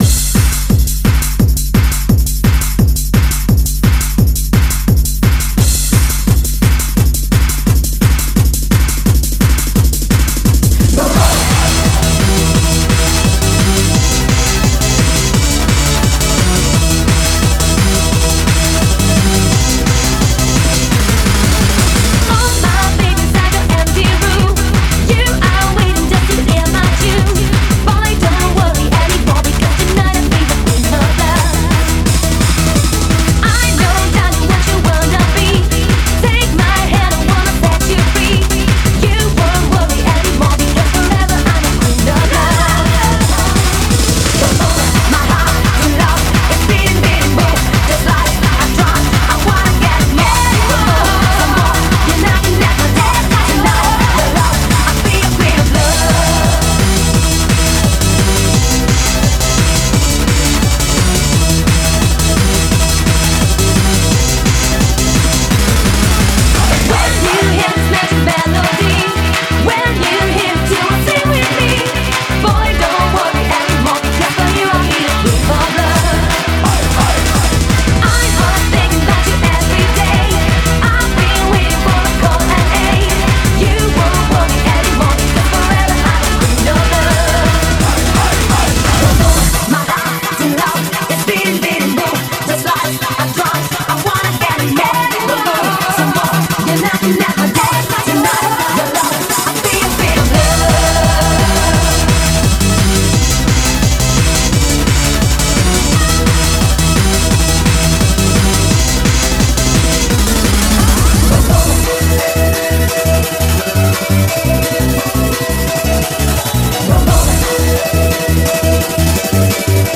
BPM172-172
Audio QualityPerfect (Low Quality)